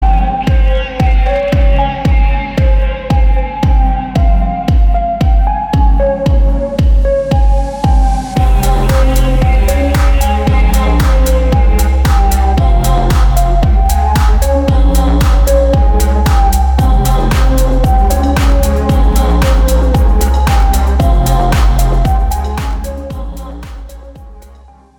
• Качество: 320, Stereo
deep house
атмосферные
Electronica